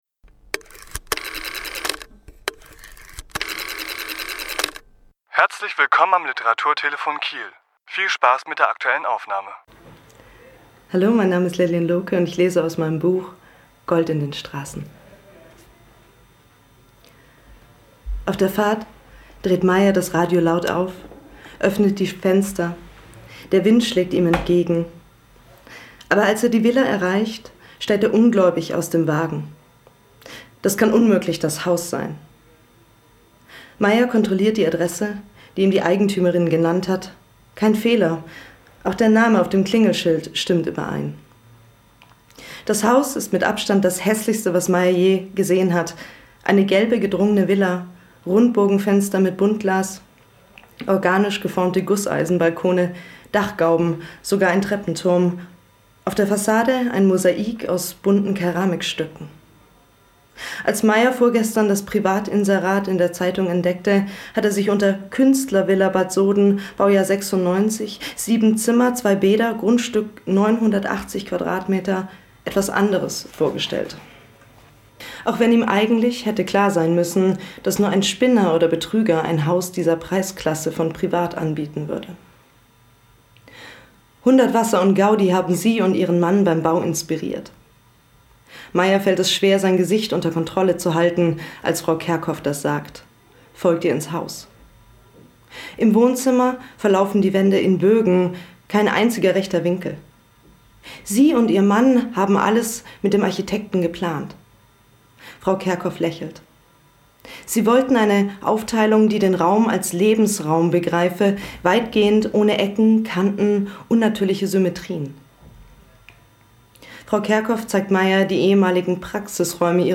Autor*innen lesen aus ihren Werken